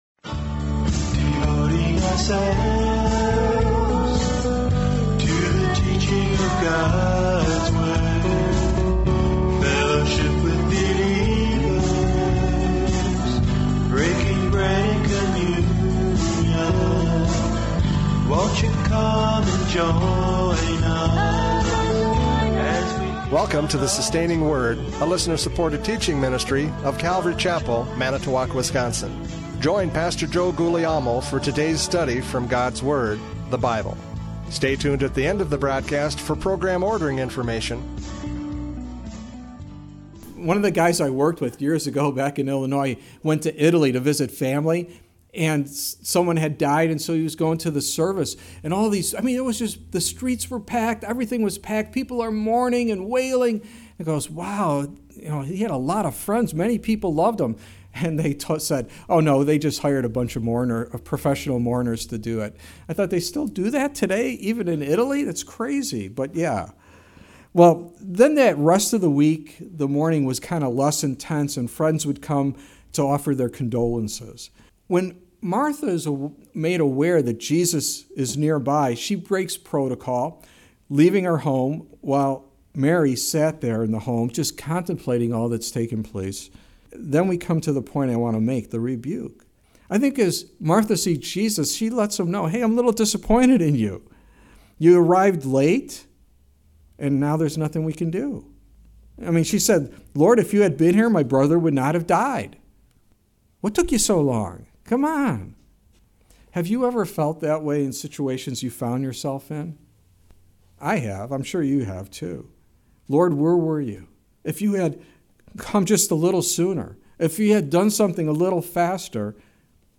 John 11:17-27 Service Type: Radio Programs « John 11:17-27 Resurrection Life!